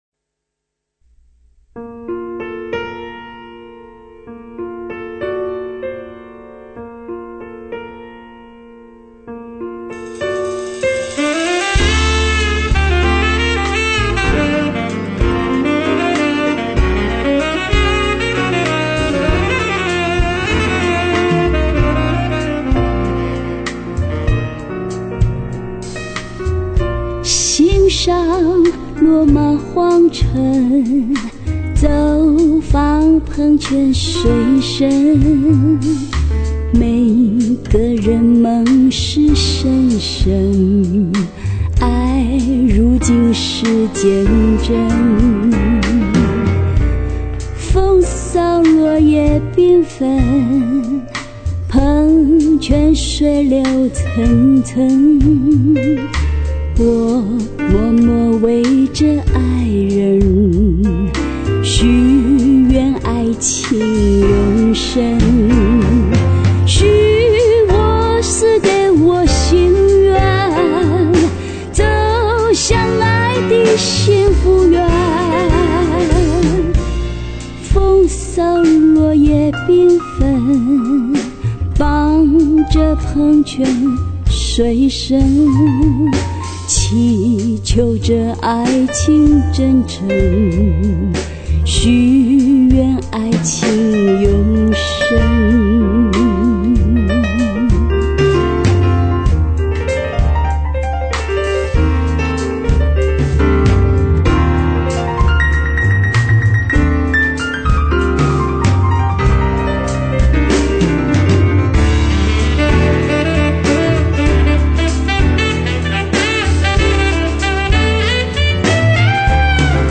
》主题曲